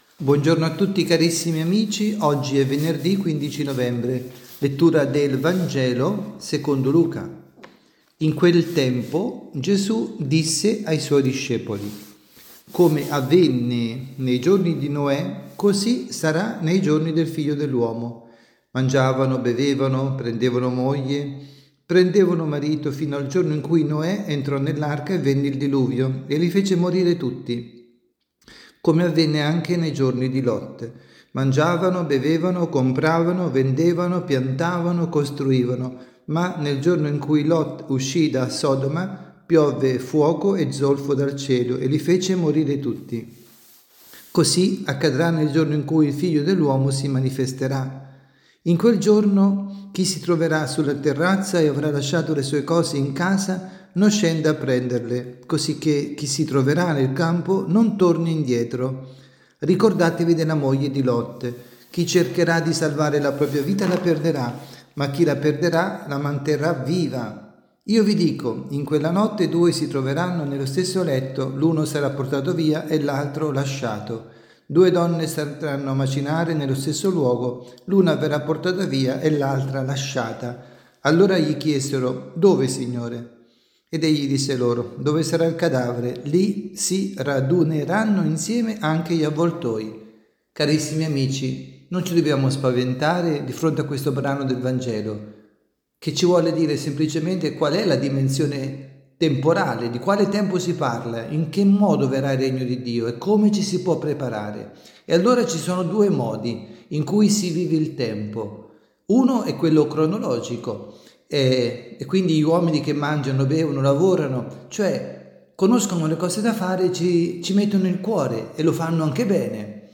Catechesi
dalla Basilica di San Nicola – Tolentino